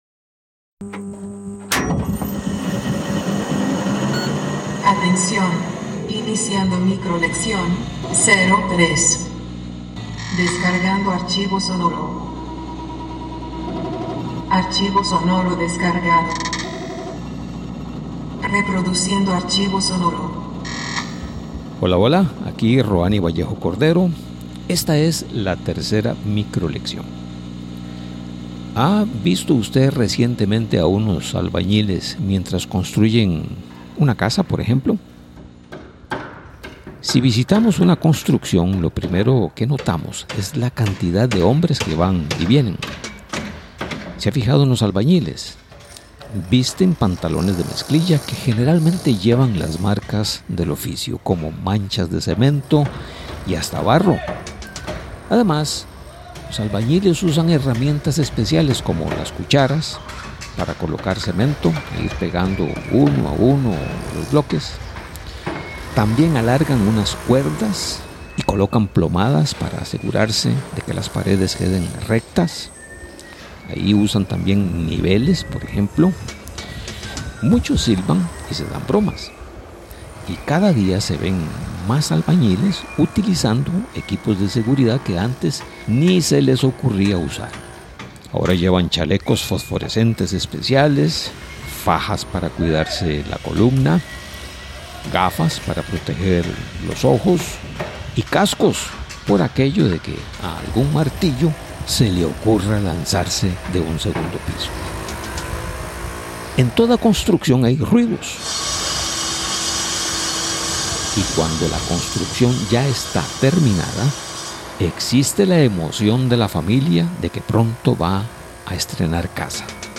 Esta es la micro-lección 03 del curso titulado: El ADN de la radio alternativa.